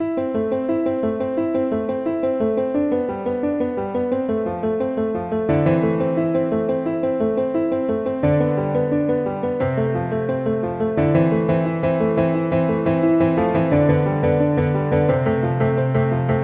DnB的钢琴循环剪辑和其他东西
描述：这是我的第一个样本，我打算把它放在这里，它是一个钢琴旋律，我在我的最新曲目中使用。
Tag: 175 bpm Drum And Bass Loops Piano Loops 2.77 MB wav Key : Unknown